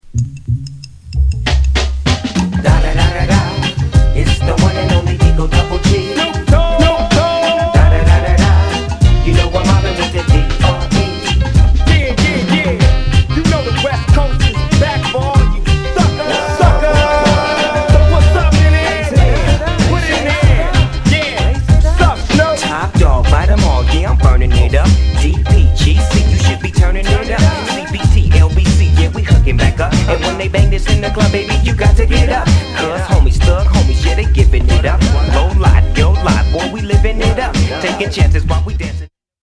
のアカペラをそのまんま、
前のめりなアーリー・レゲー・チューンに、レイド・バッキンな